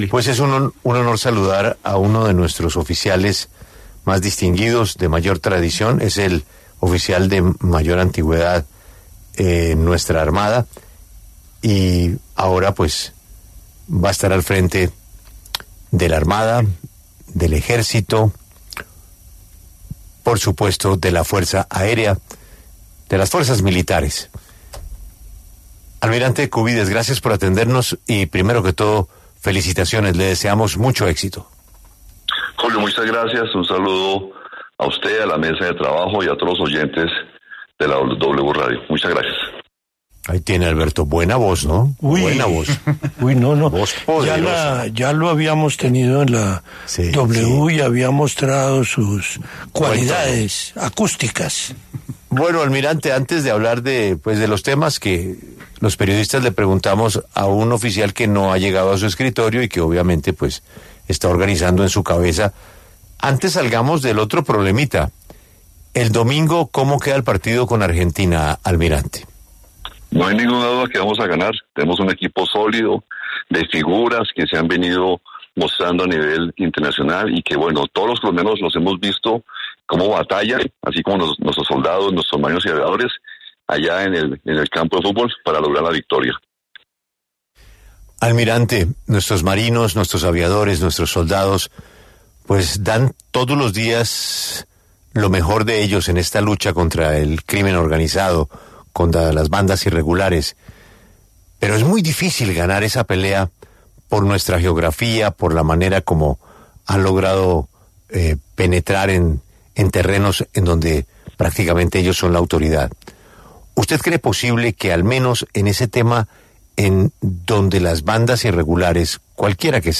En diálogo con La W, el almirante Francisco Cubides habló de los nuevos retos tras tomar el mando de la Fuerza Aérea, el Ejército y la Armada.